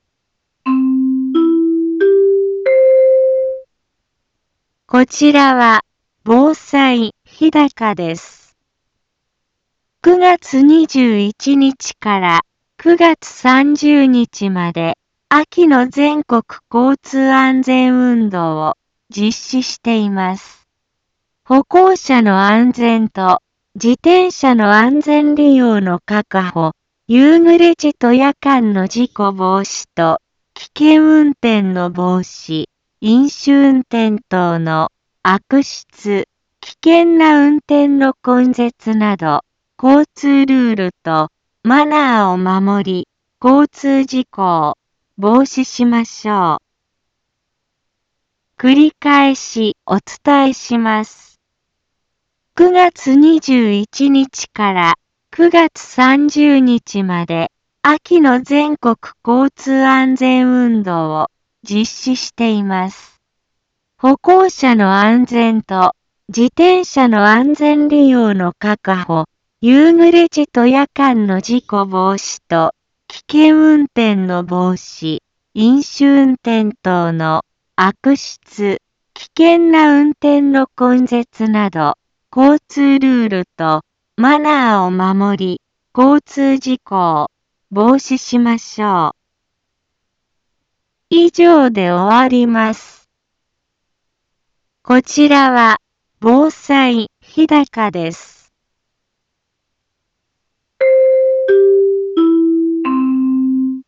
一般放送情報
Back Home 一般放送情報 音声放送 再生 一般放送情報 登録日時：2021-09-21 15:03:40 タイトル：交通安全のお知らせ インフォメーション：こちらは防災日高です。 ９月21日から９月30日まで「秋の全国交通安全運動」を実施しています。